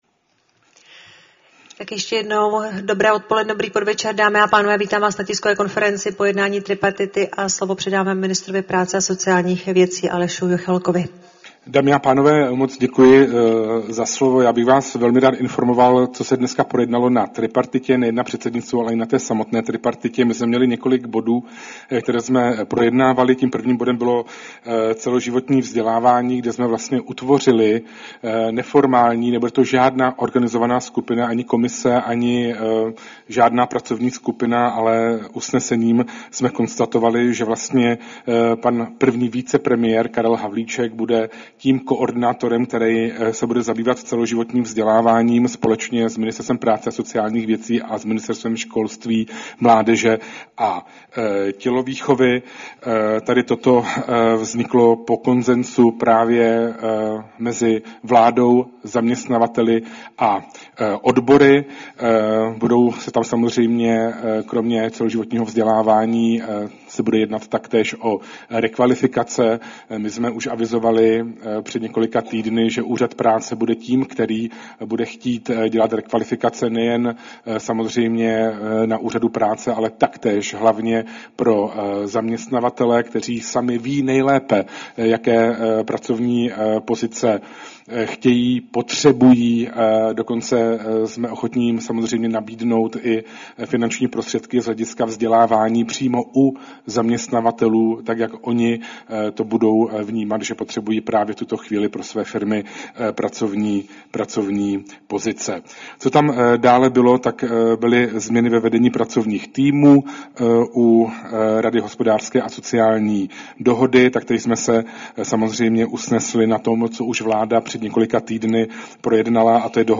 Tisková konference po plenární schůzi Rady hospodářské a sociální dohody ČR, 9. března 2026